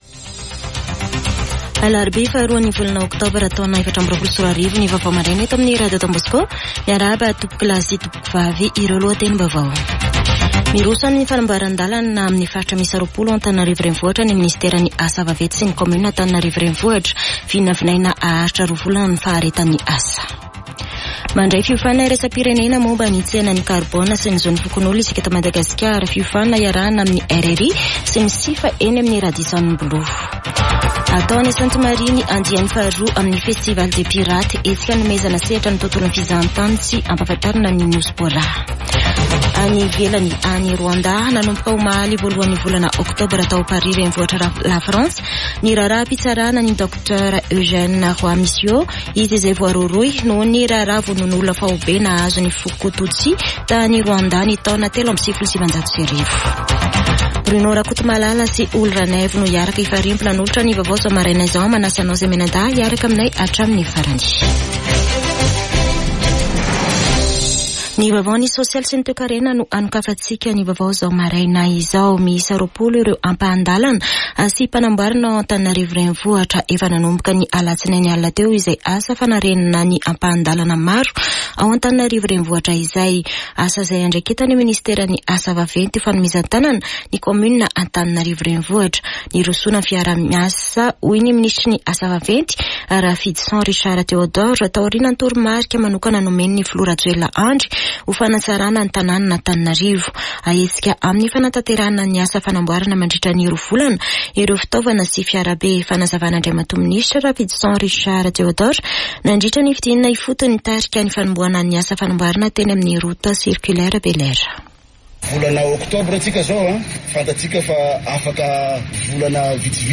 [Vaovao maraina] Alarobia 2 oktobra 2024